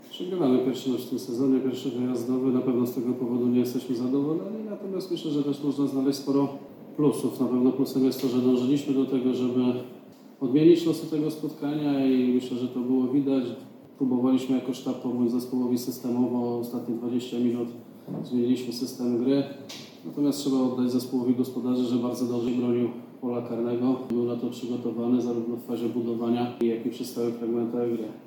na konferencji prasowej tak podsumował spotkanie